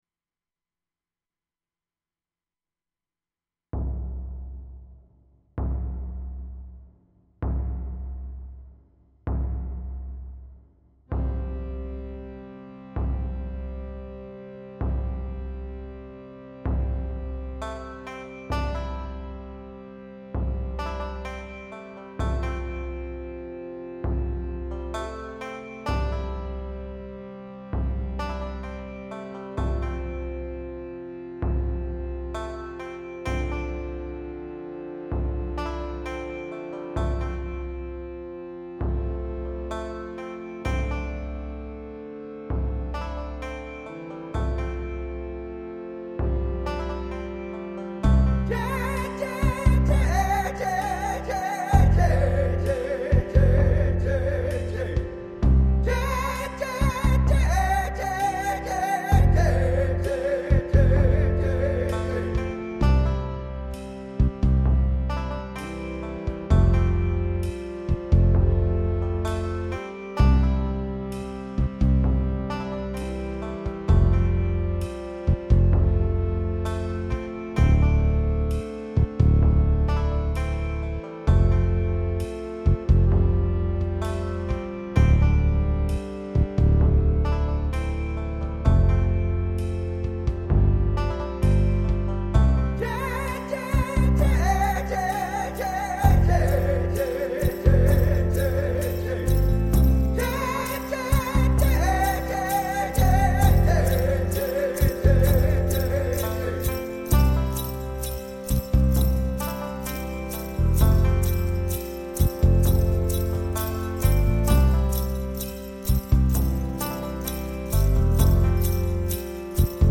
Contemporary Music